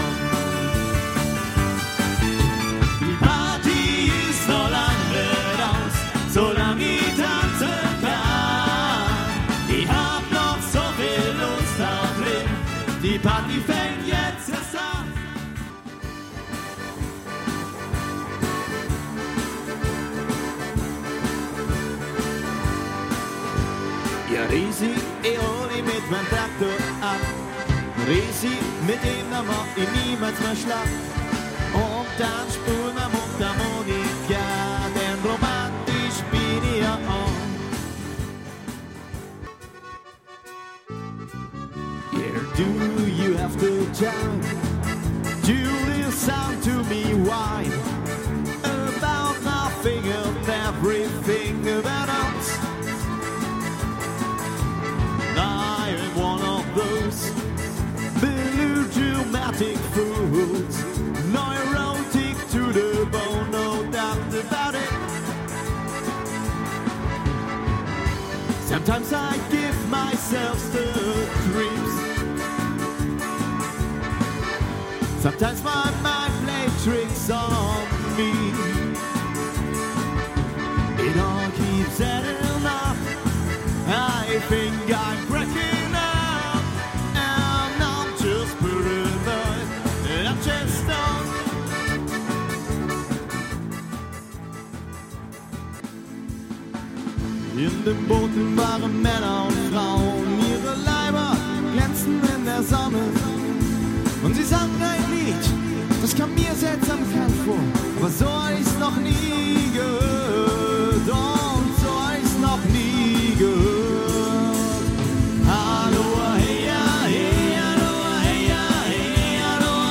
Und das alles selbstverständlich 100% LIVE!
• Coverband
• Allround Partyband